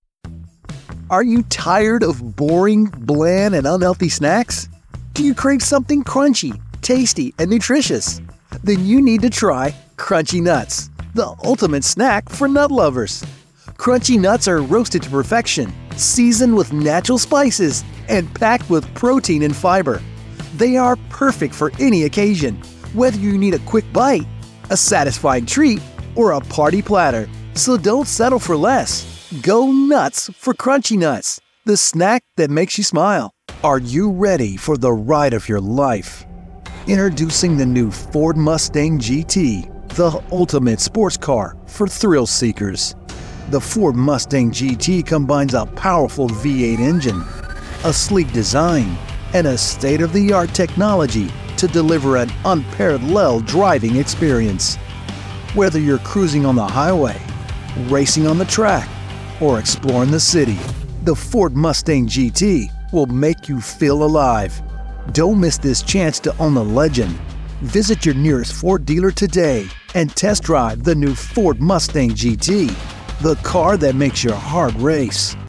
I can deliver a wide range of styles, from friendly and conversational to authoritative and dramatic.
American General accent, American Southern accent, American West accent, American West Coast accent